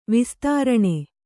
♪ vistāraṇe